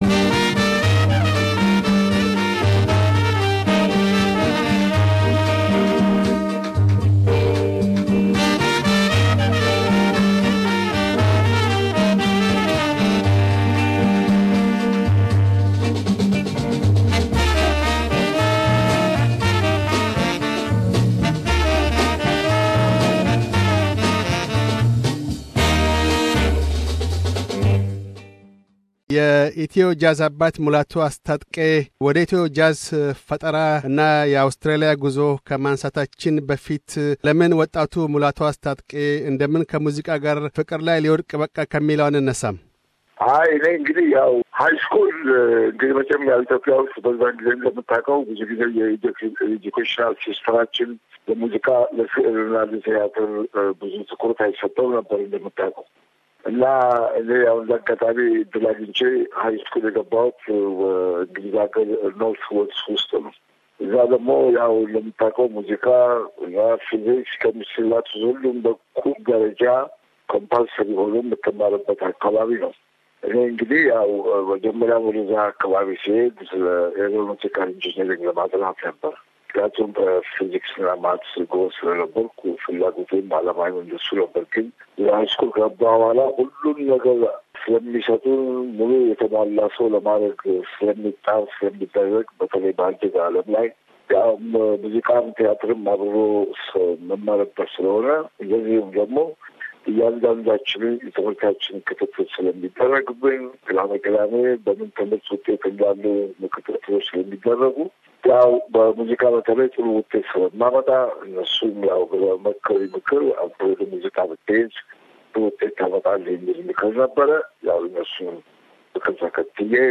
Interview with Mulatu Astatke